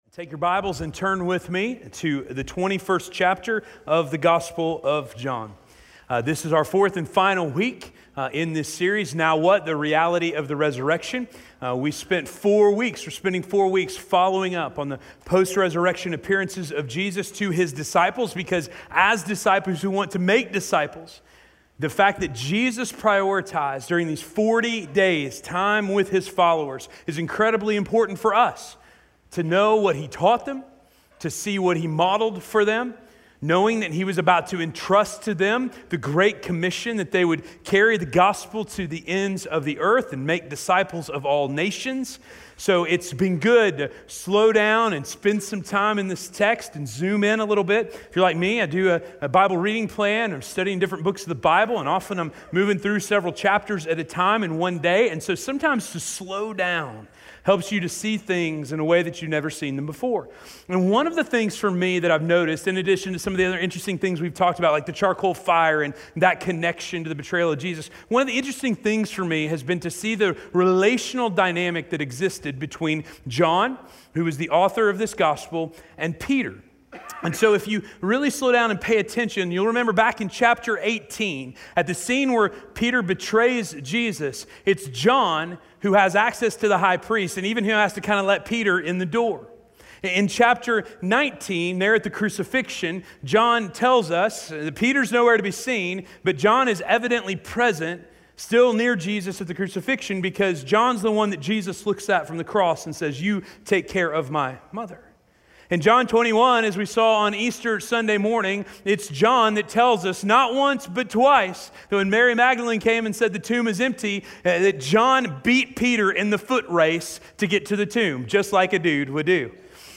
Keep Your Eyes on Your Own Page - Sermon - Station Hill